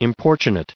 added pronounciation and merriam webster audio
1600_importunate.ogg